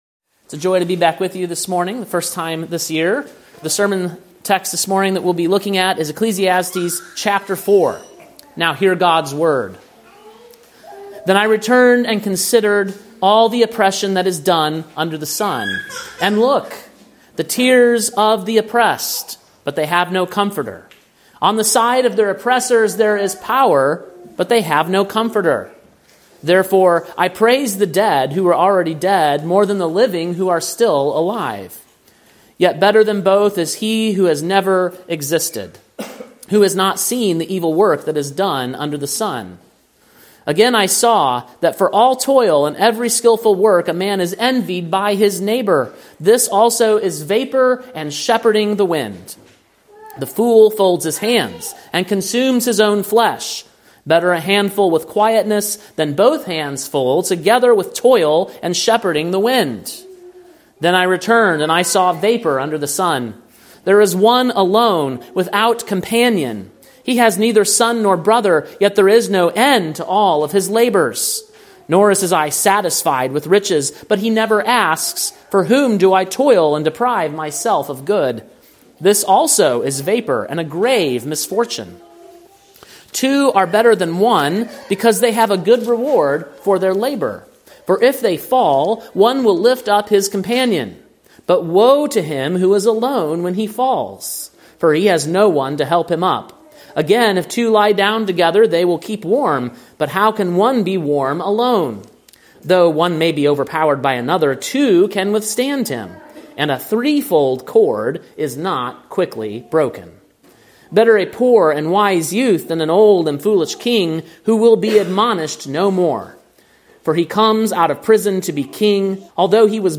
Sermon preached on March 8, 2025, at King’s Cross Reformed, Columbia, TN.